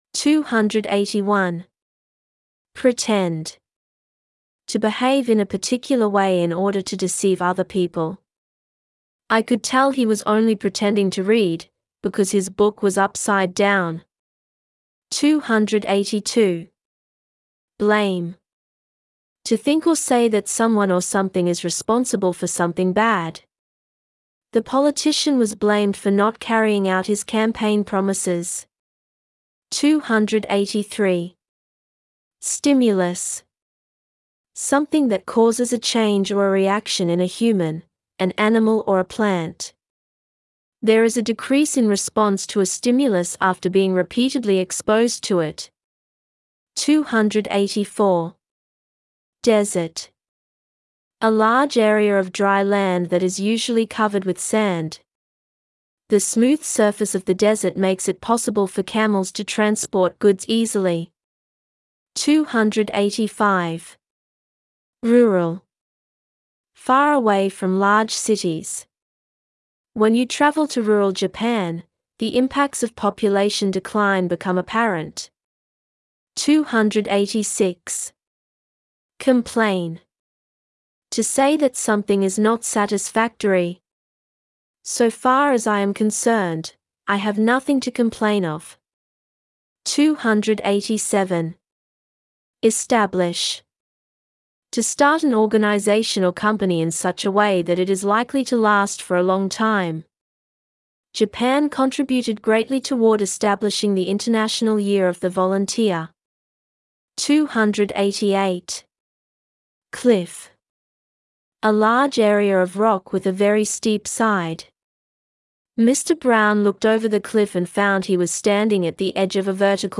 ネイティブ音声が共通テスト英語キーワードを単語・英英定義・例文の順で読み上げるyoutube 【共通テスト英語キーワード820】共通テスト英語キーワード820単語・英英定義・例文音声 を作成しました。